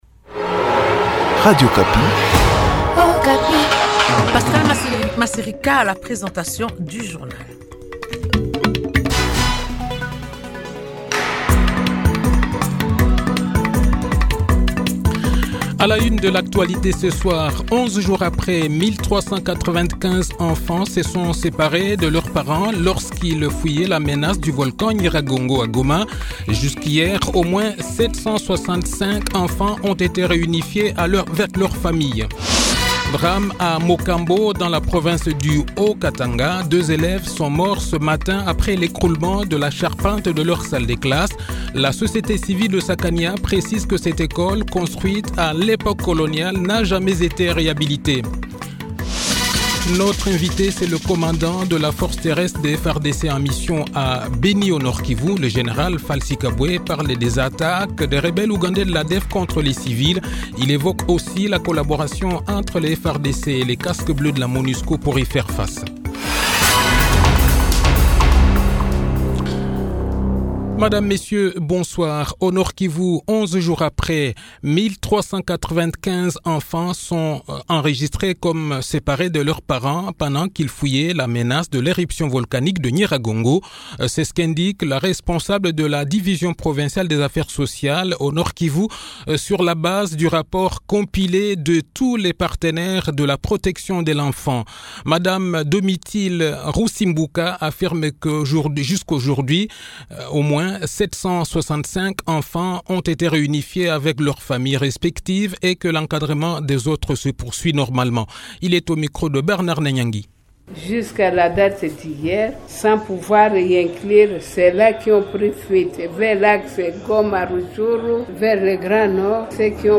Le journal de 18 h, 1er Juin 2021